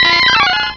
Cri de Férosinge dans Pokémon Rubis et Saphir.